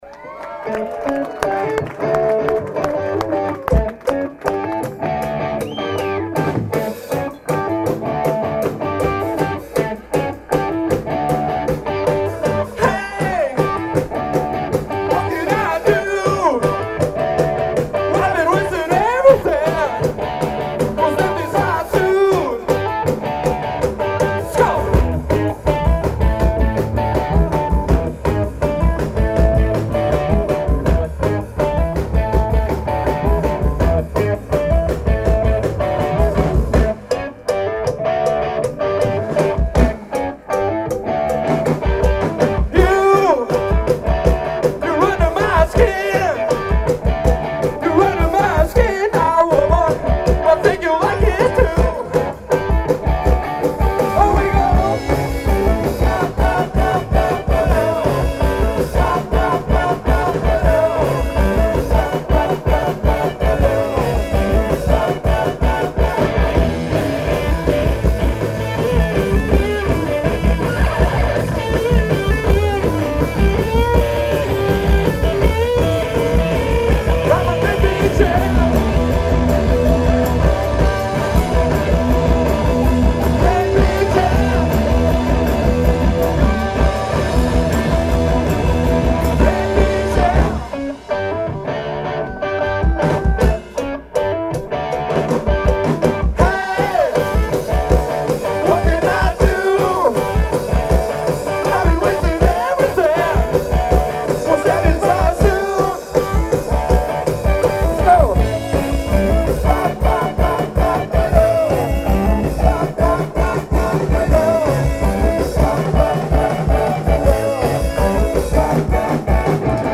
Khyber – November 5, 2002